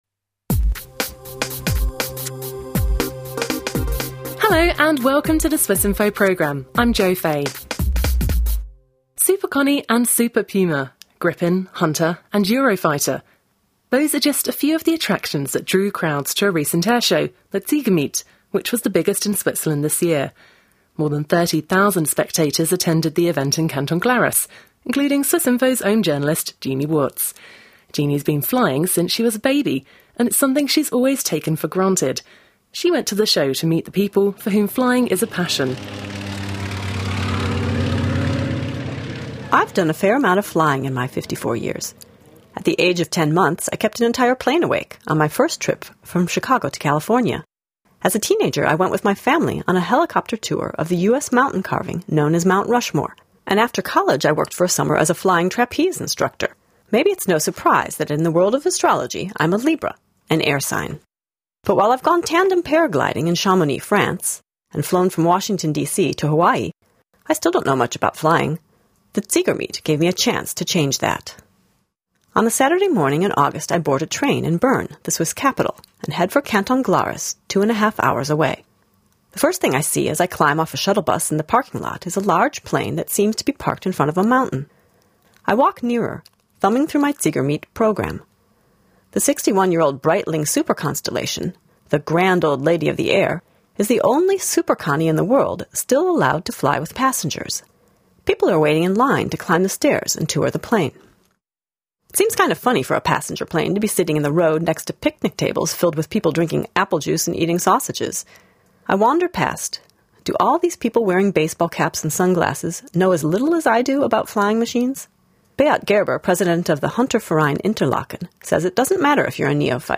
Why did 30,000 people show up for Switzerland's biggest airshow of the year? Pilots and spectators share their knowledge and experiences.